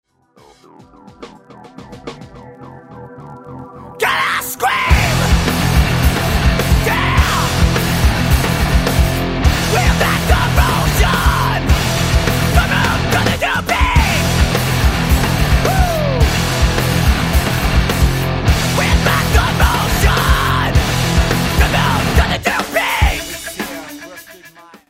• Качество: 128, Stereo
громкие
мощные
Драйвовые
hardcore
punk rock
Стиль: хардкор-панк (hardcore punk)